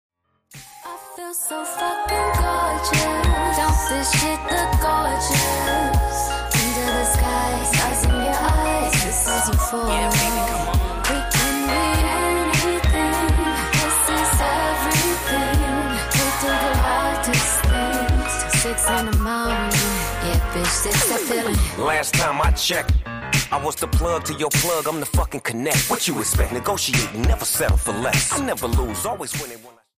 Hip-Hop / Urban